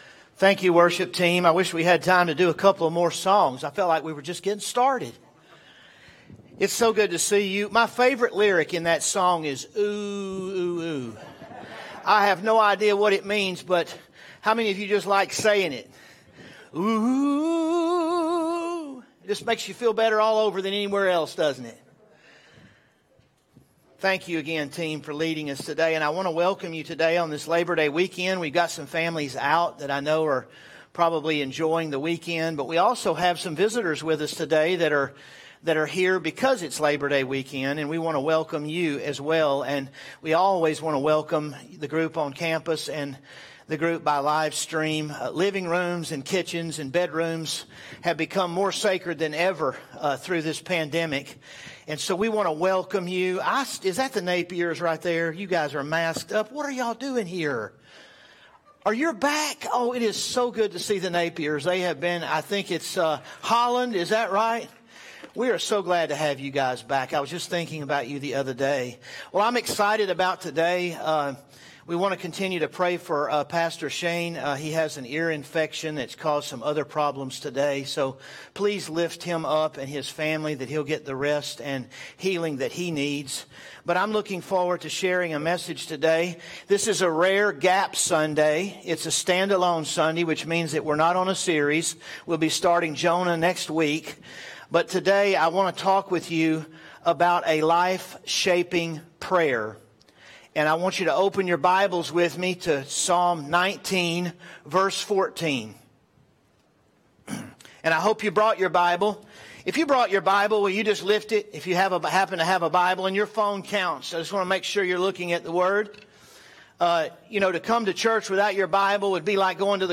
From Series: "Standalone Sermons "